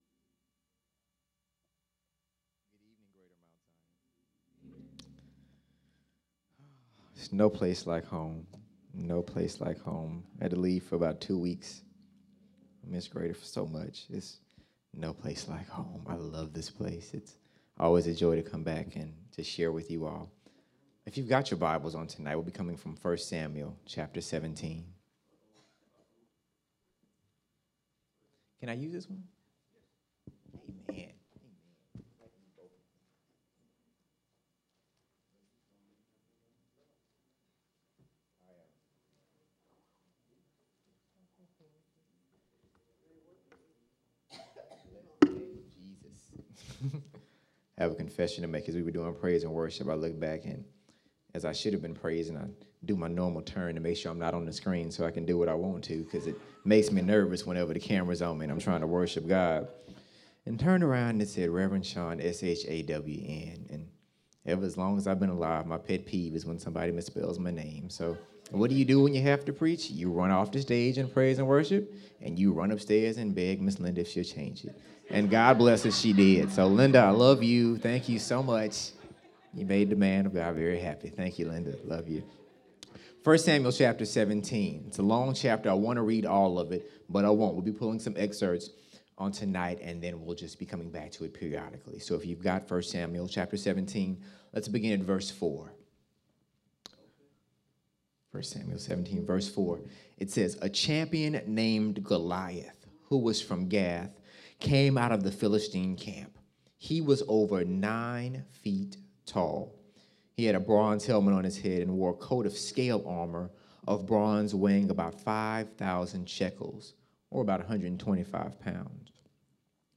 This is a collection of sermons I have preached over the years in various locations.